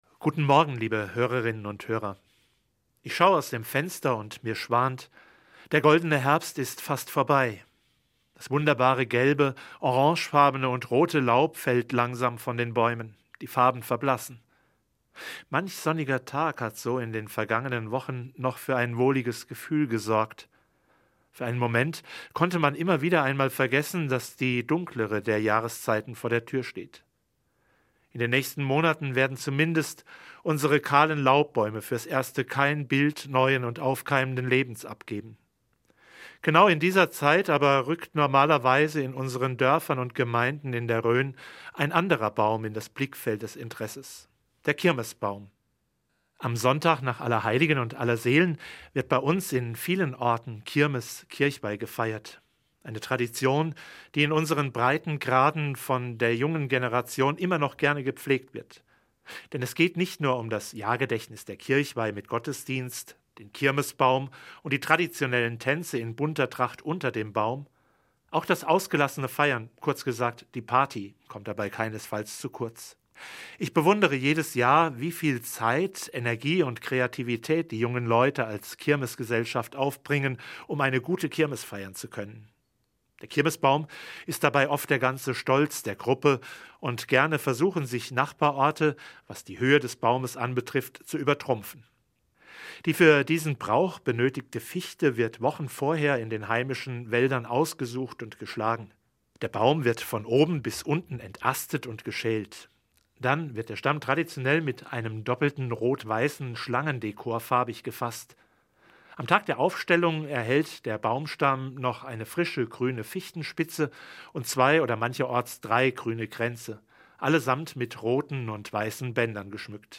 MORGENFEIER